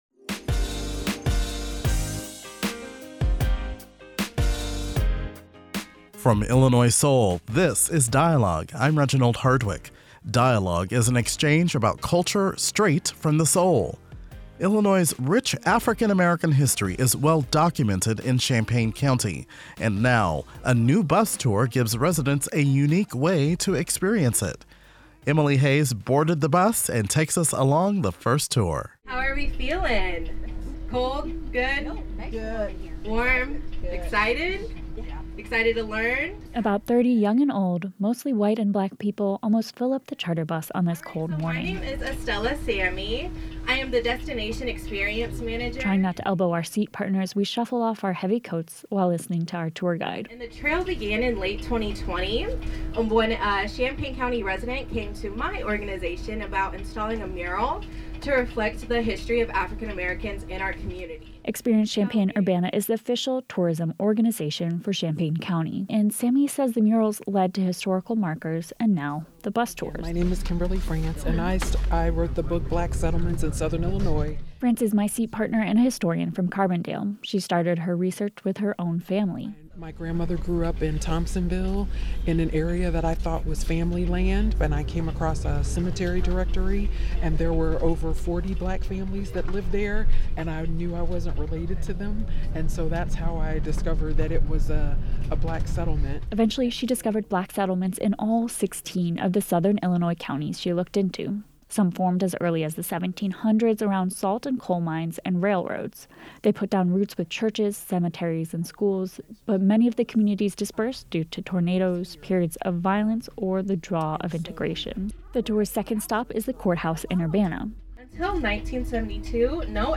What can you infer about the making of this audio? This segment of Dialogue goes inside the first public bus tour of the Champaign County African American Heritage Trail.